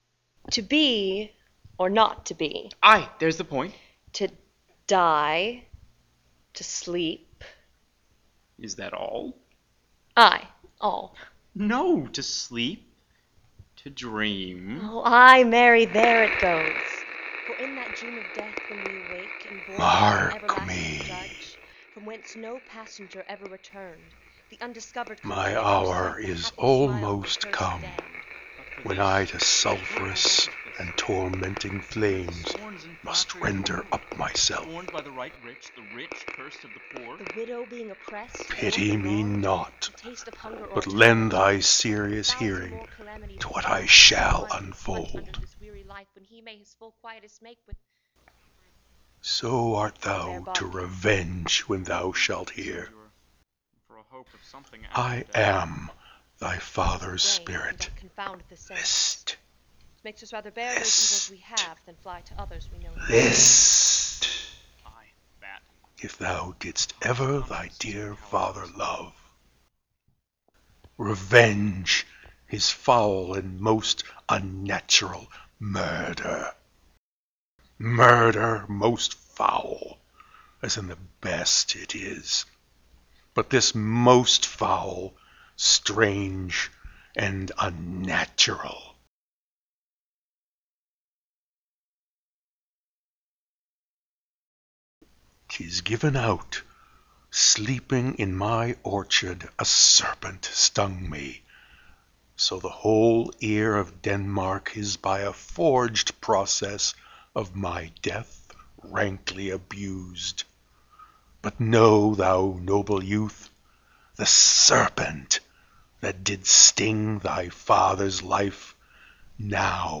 Since we didn’t get Hamlet’s responses on tape, I’ve included a portion of the script below.
[voice-over]
Static.